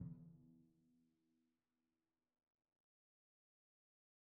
Percussion / Timpani
Timpani4_Hit_v1_rr2_Sum.wav